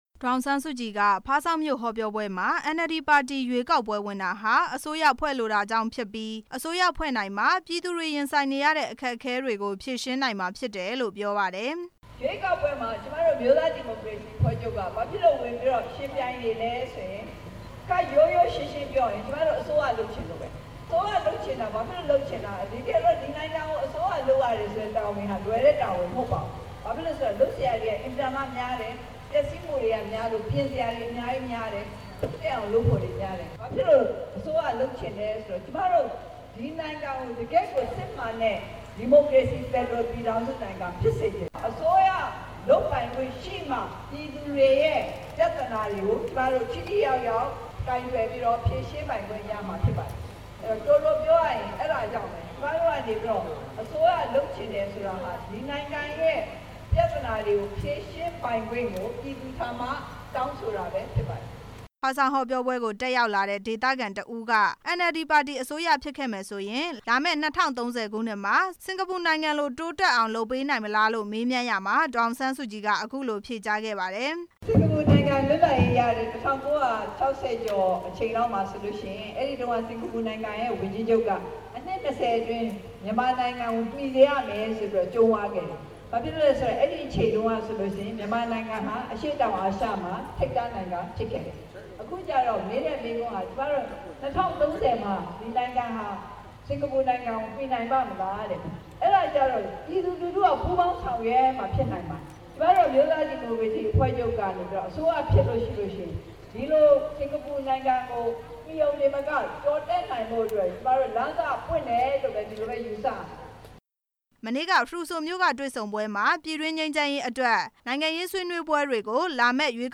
ကယားပြည်နယ် ဖားဆောင်းမြို့မှာ ကျင်းပတဲ့ ရွေးကောက်ပွဲအောင်နိုင်ရေး ဟောပြောပွဲမှာ ဒေါ်အောင်ဆန်း စုကြည်က ပြောခဲ့တာဖြစ်ပါတယ်။